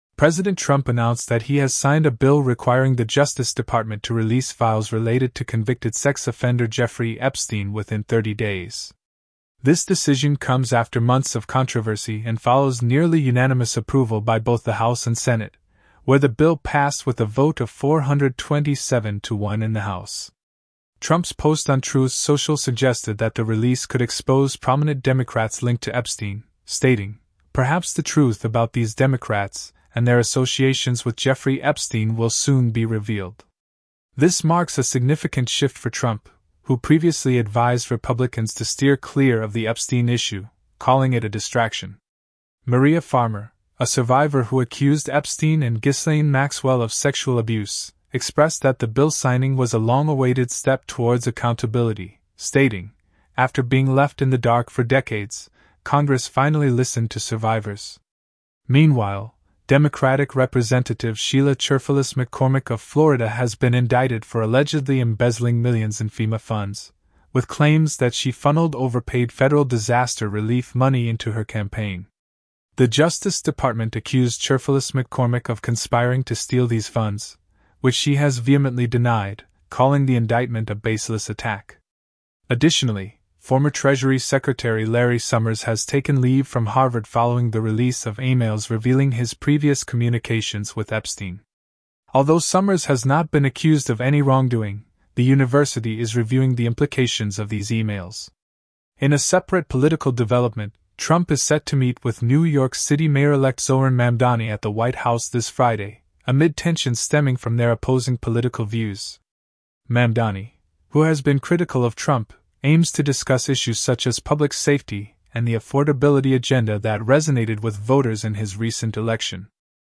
Top News Summary